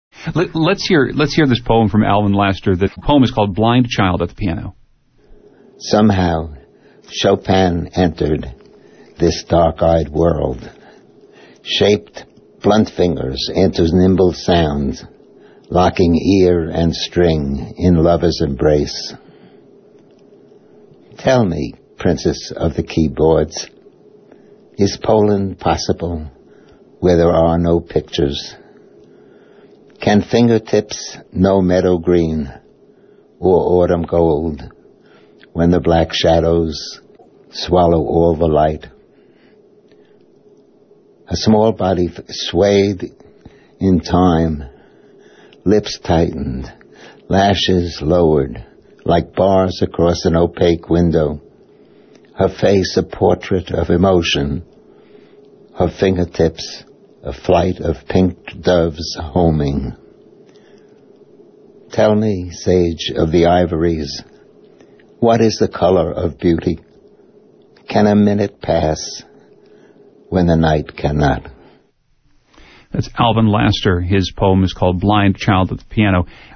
Audio interview broadcast on WNPR radio's Where We Live January 17, 2008.